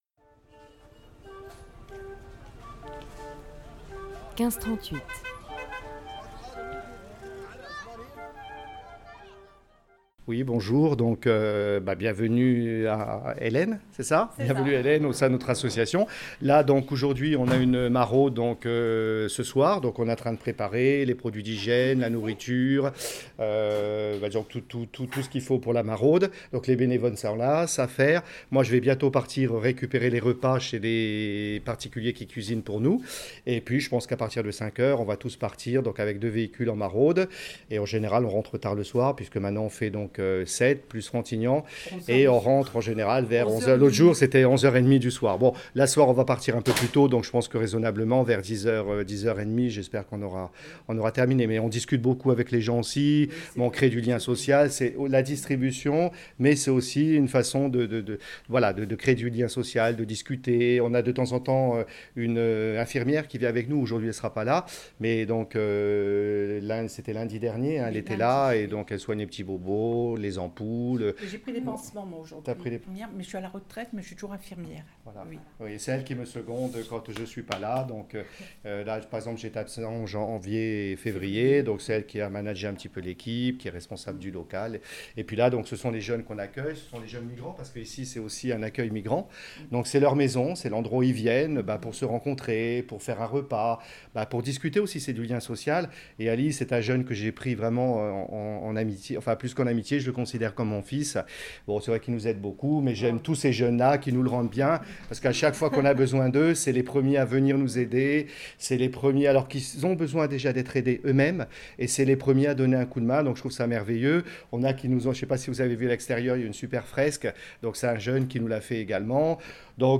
A Sète, reportage sonore au cœur d'une maraude post confinement |